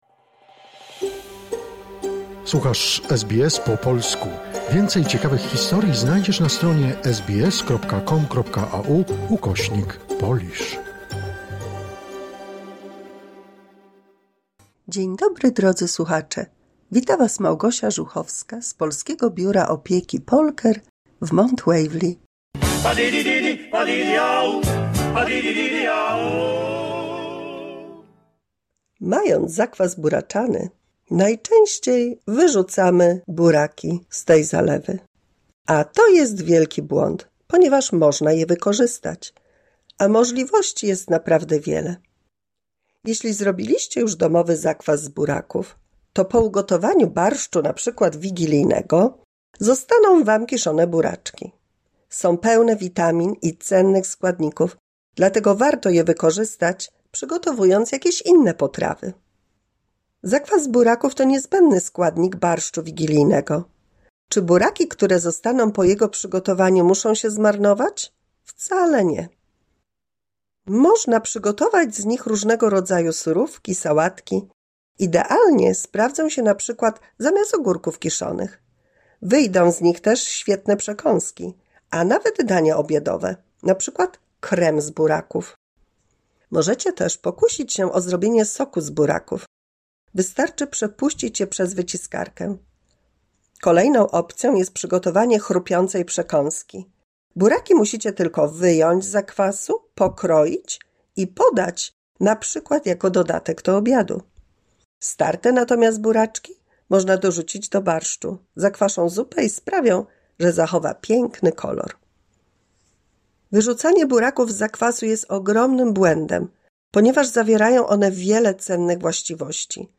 183 słuchowisko dla polskich seniorów